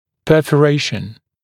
[ˌpɜːfə’reɪʃn][ˌпё:фэ’рэйшн]перфорация, прободение